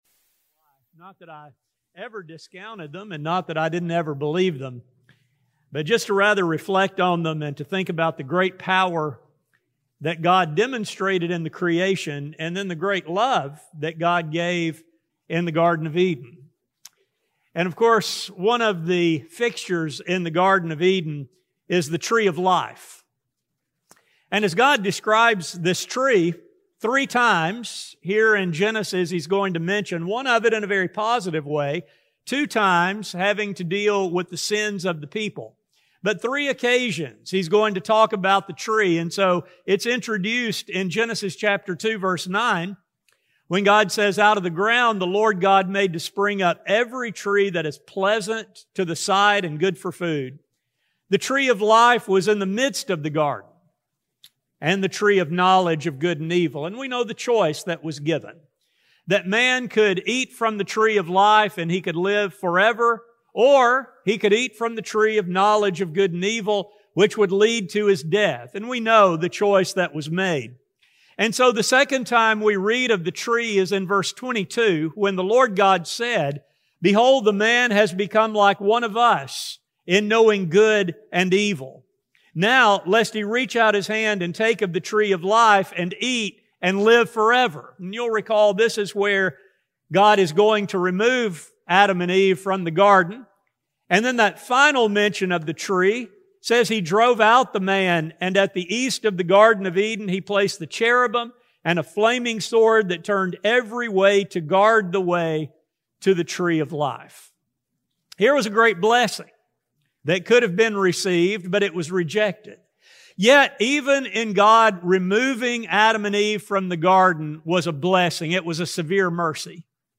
This lesson centers on these proverbs that help to see wisdom’s association with the tree. A sermon recording